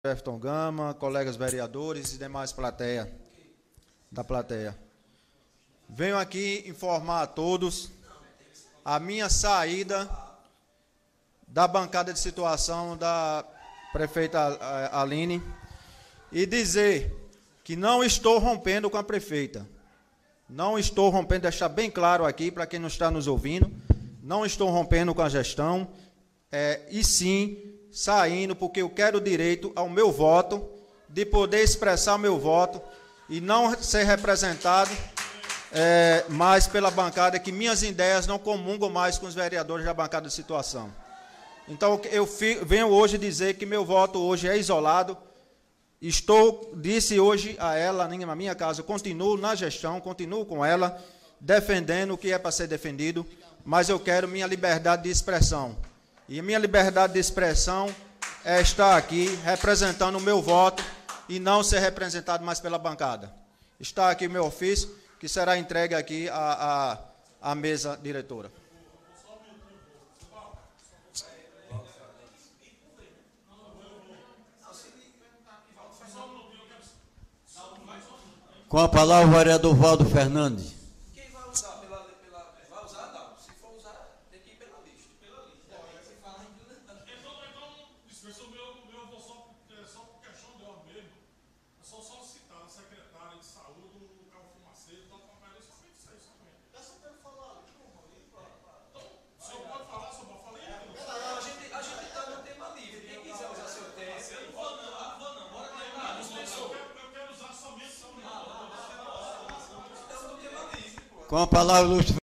O vereador Aerton Ferreira (PROS) afirmou durante a sessão especial desta terça-feira (7) que está deixando a bancada de sustentação da prefeita do município de Belém, Aline Barbosa.